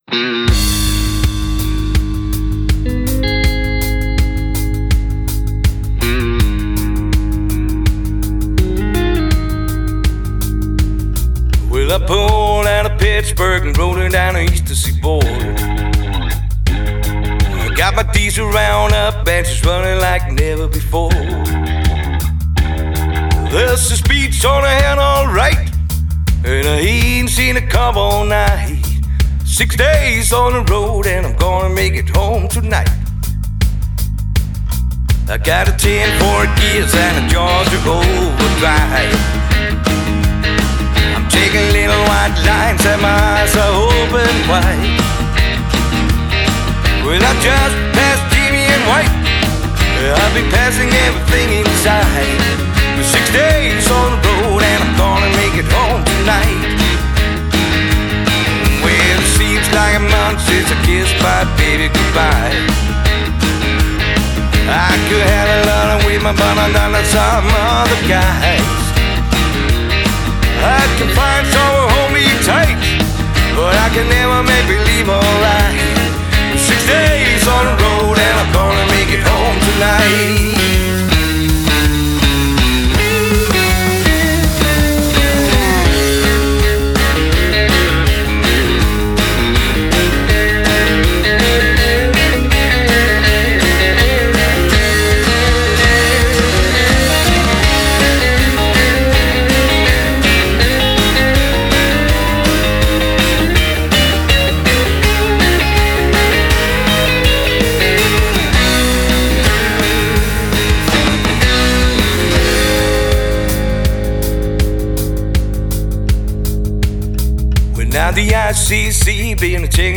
• Coverband
• Dansband